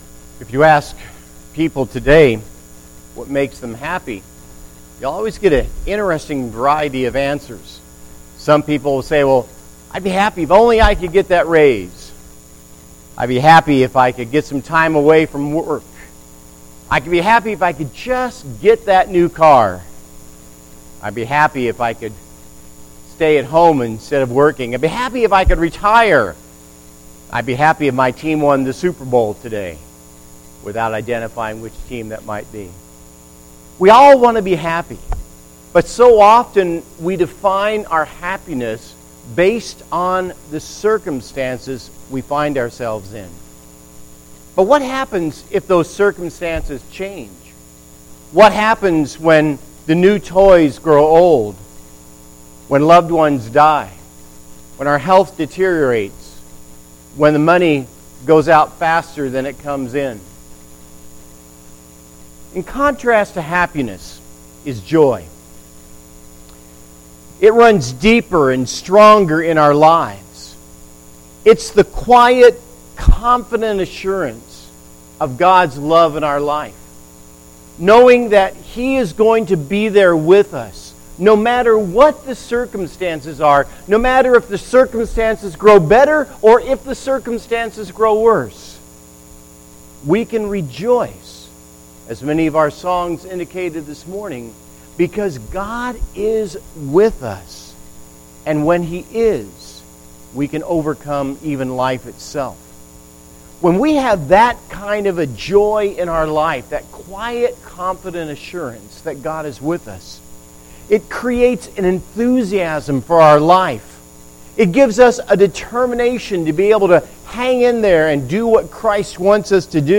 Audio Sermons - Babcock Road Christian Church